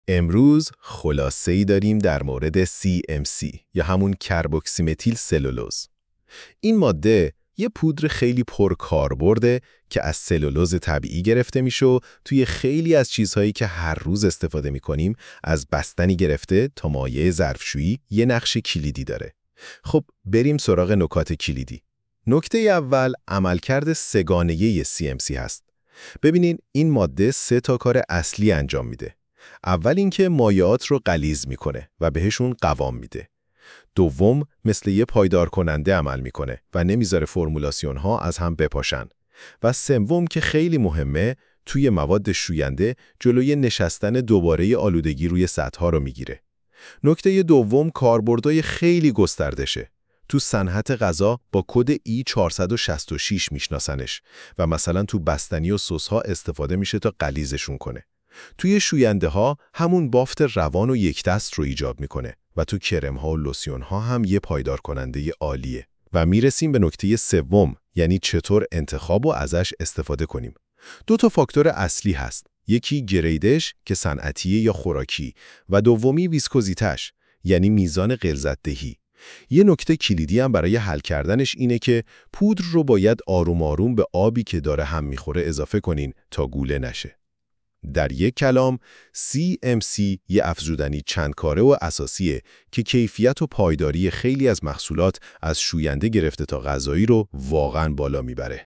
CMC-Key-Points-Summary-Voiceover.mp3